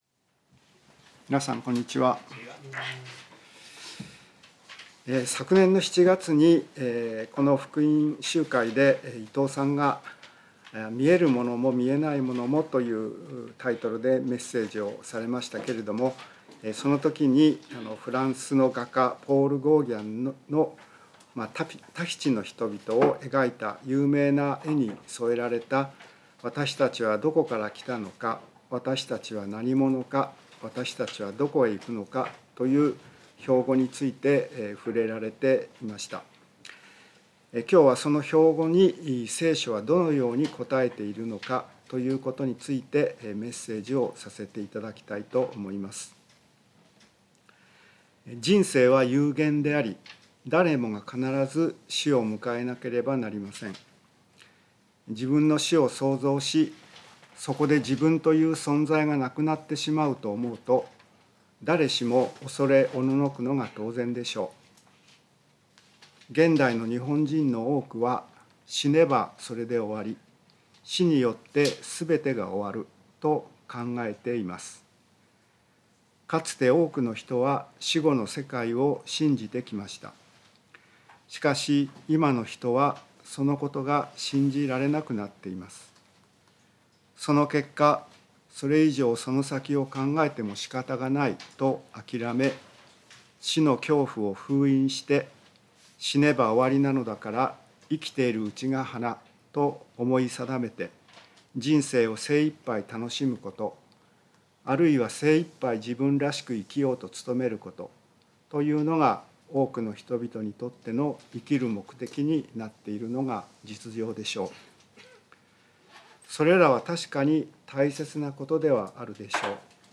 聖書メッセージ No.259